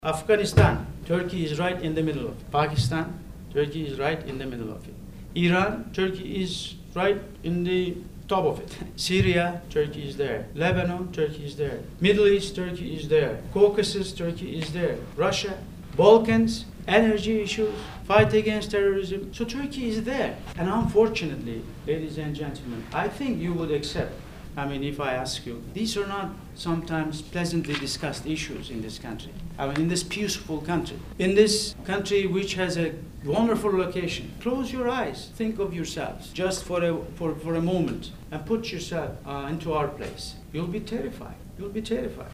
Newly appointed Turkish ambassador Namik Tan spoke to a crowd of USC students and professors this week. He described how he applies public diplomacy to the challenges facing his country. He says Turkey's geography has a great impact on its progress.